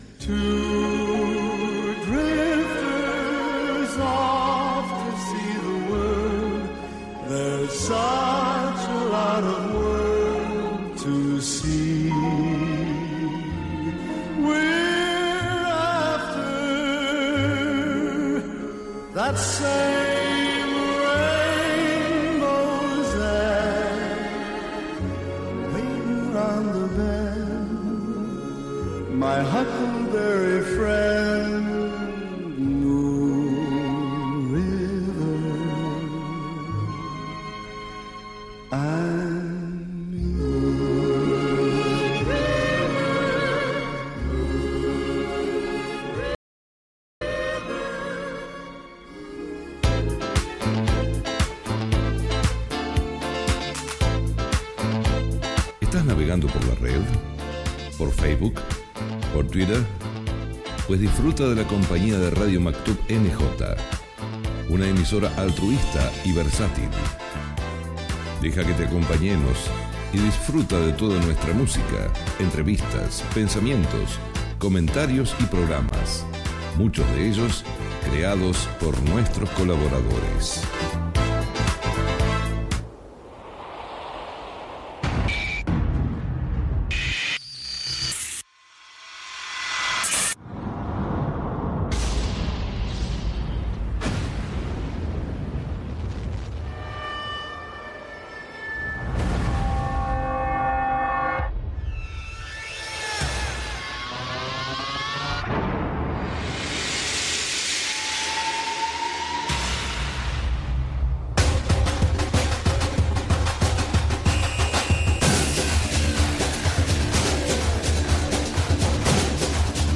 Tema musical, indicatiu i tema musical